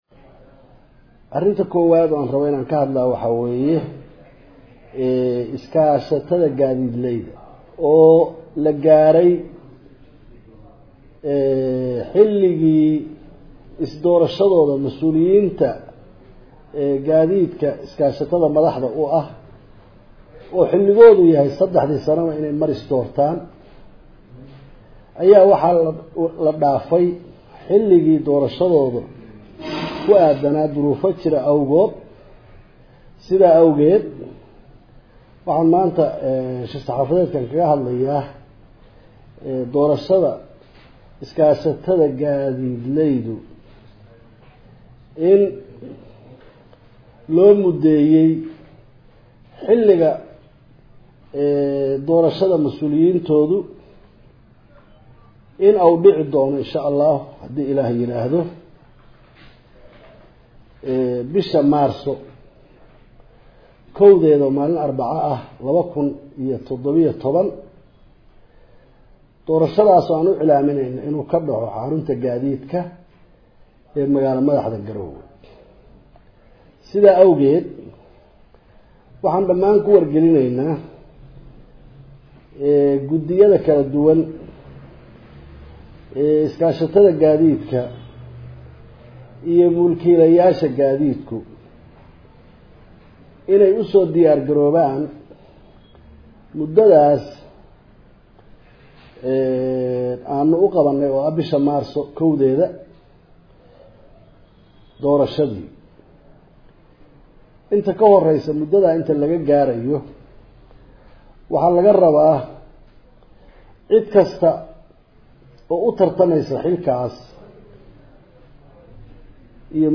Hoos Ka dhagayso Codka Wasiir C/rishiid iskashatada uga hadlay